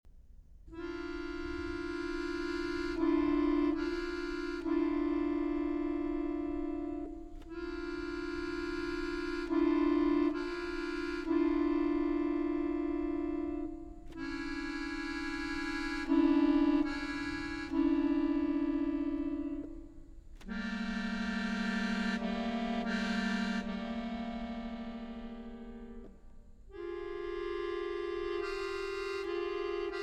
Sonata for bayan in 3 movements (2004)